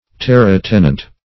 Terre-tenant \Terre"-ten`ant\, n. [F. terre earth, land +